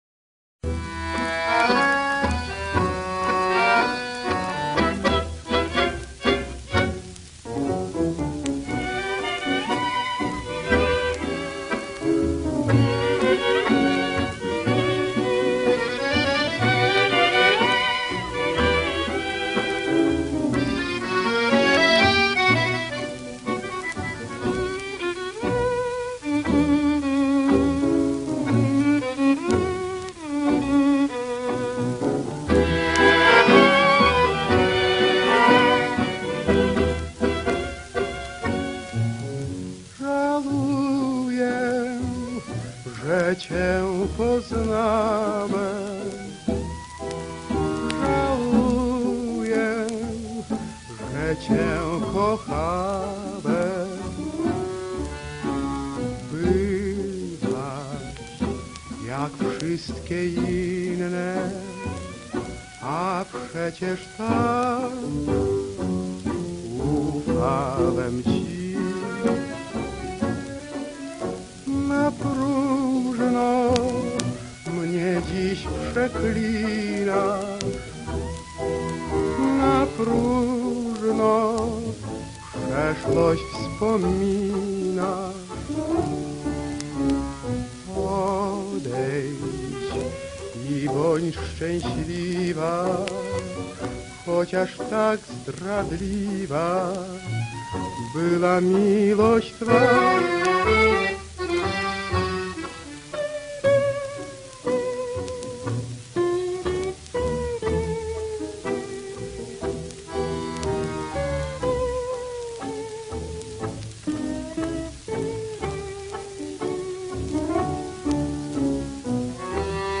Довоенное польское танго, красивое и грустное